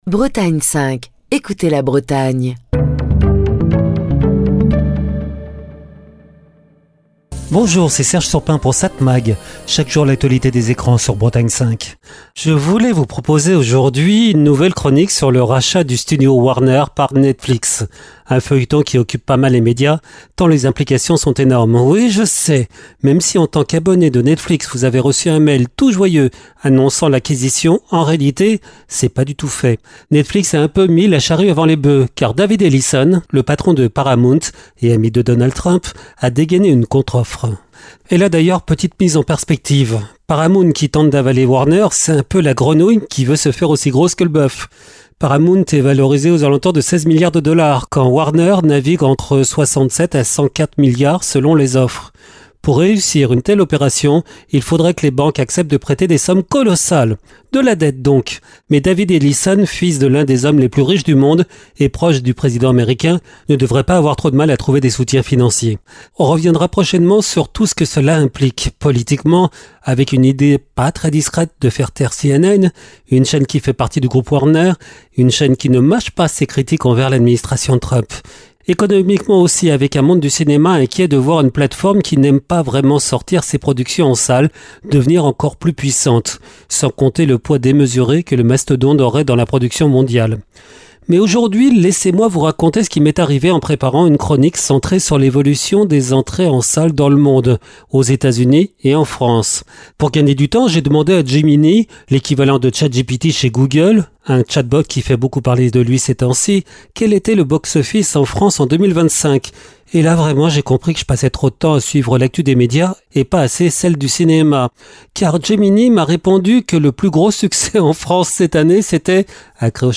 Chronique du 10 décembre 2025.